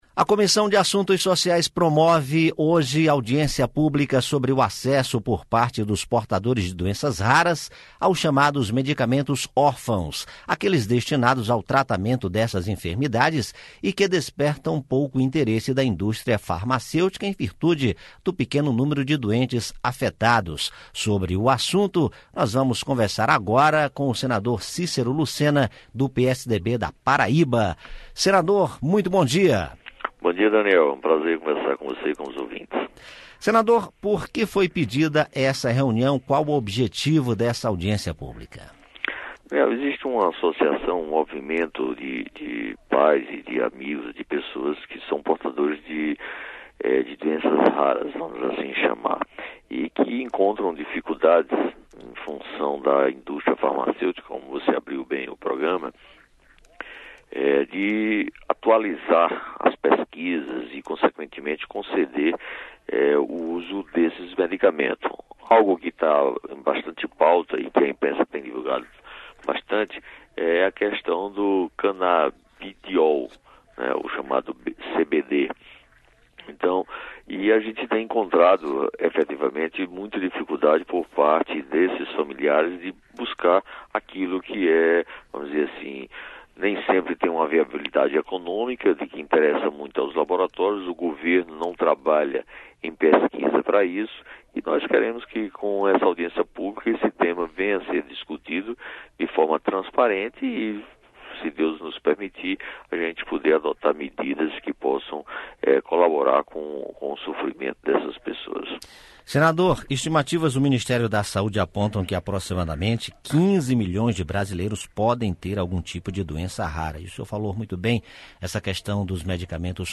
Entrevista com o senador Cícero Lucena (PSDB-PB).